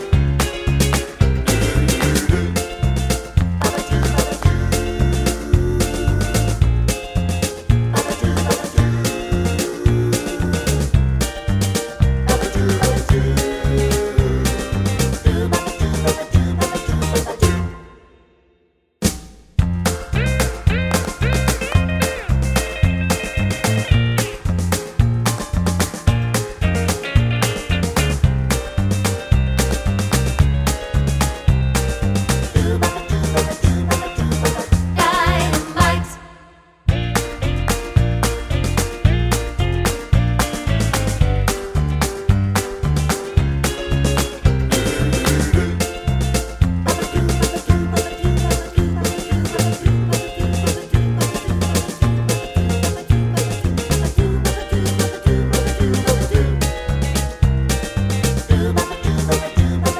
no Backing Vocals Pop (1960s) 2:09 Buy £1.50